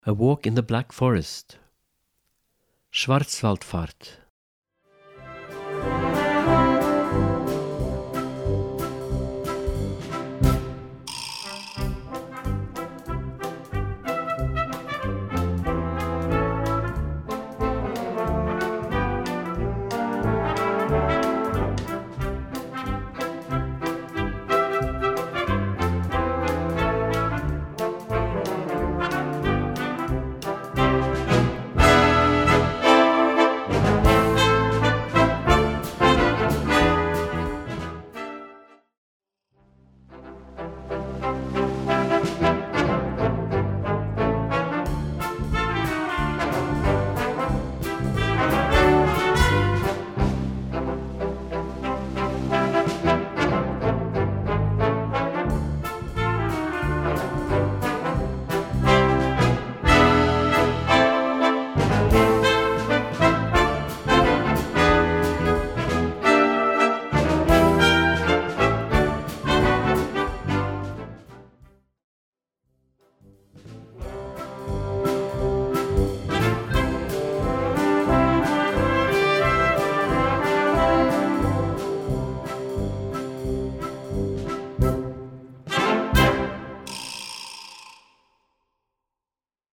Gattung: Jugendwerk
Besetzung: Blasorchester